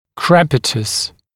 [ˈkrepətəs][ˈкрэпэтэс]крепитация